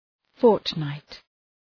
Προφορά
{‘fɔ:rtnaıt}